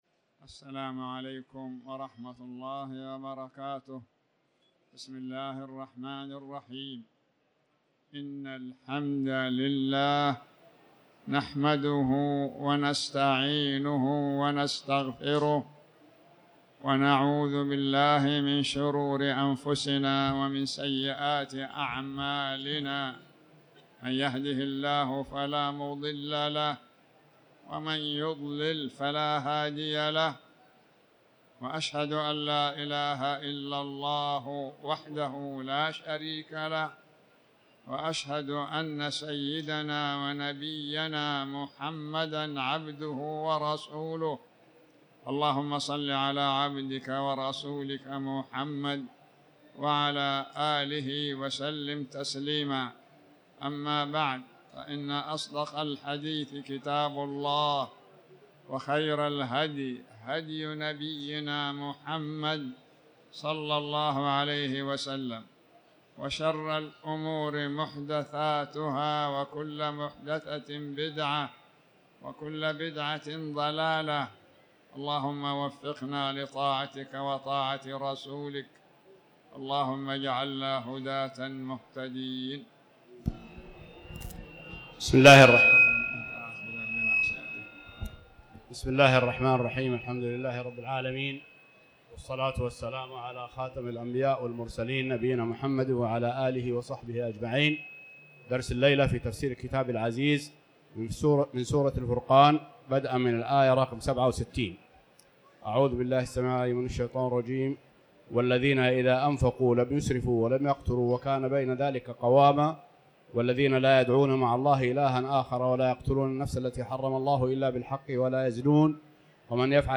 تاريخ النشر ٢٣ شوال ١٤٤٠ هـ المكان: المسجد الحرام الشيخ